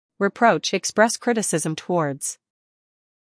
英音/ rɪˈprəʊtʃ / 美音/ rɪˈproʊtʃ /